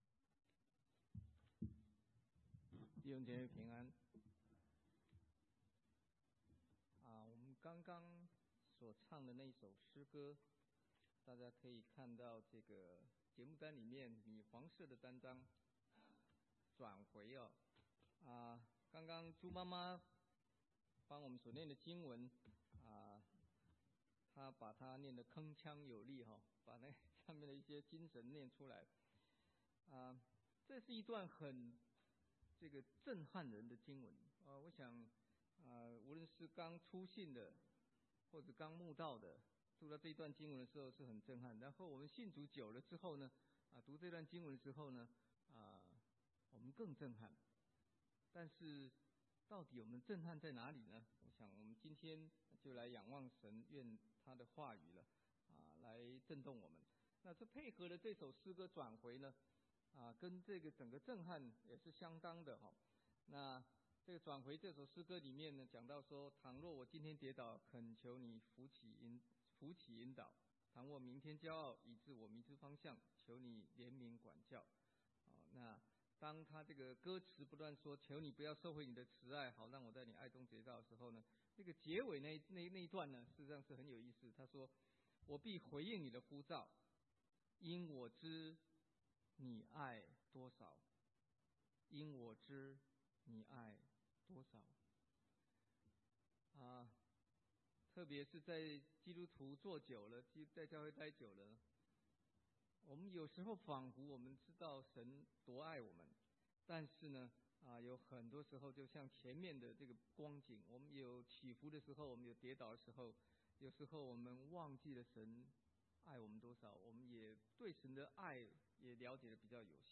華埠國語堂